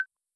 select-expand.wav